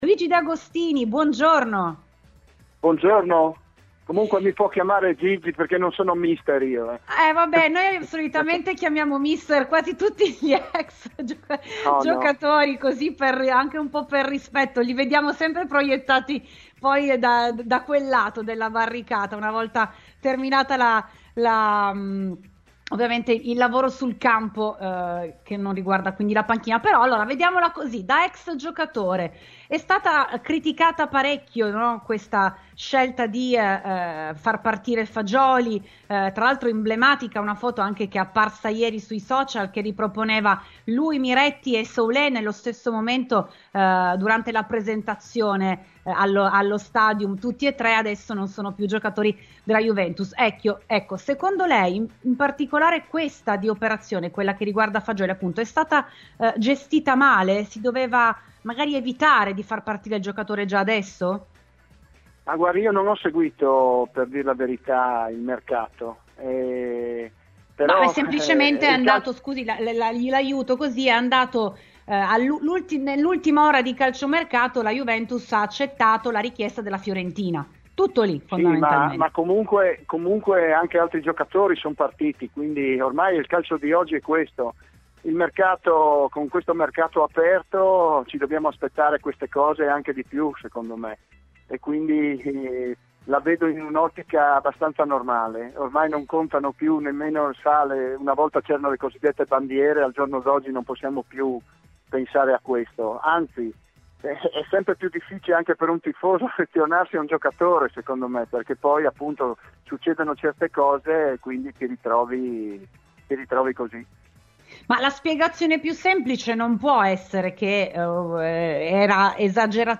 Ne abbiamo parlato con un ex giocatore che tra le tante maglie ha vestito quella juventina negli anni 90, collezionando 146 presenze e segnando 20 reti (non male per un terzino): Luigi De Agostini.